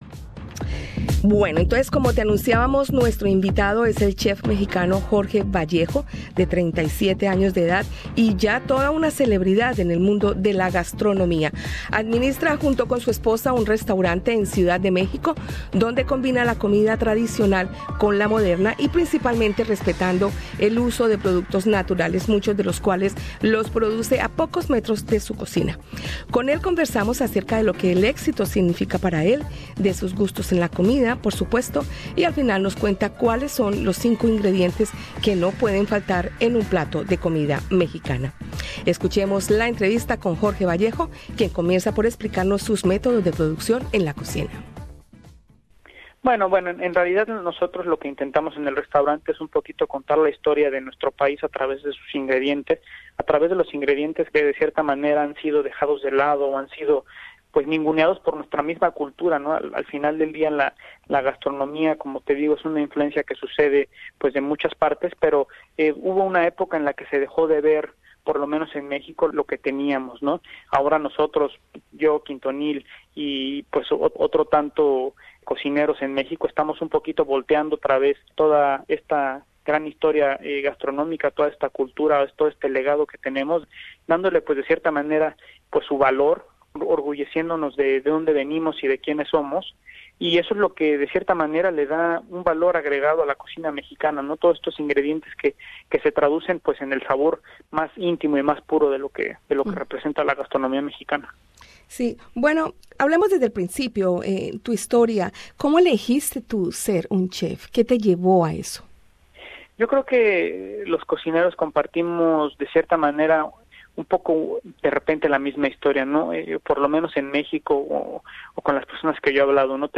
Entrevista con el chef mexicano, Jorge Vallejo, propietario del restaurante número 12 en la clasificación mundial, quien visitó Australia para participar en el Melbourne Food and Wine Festival.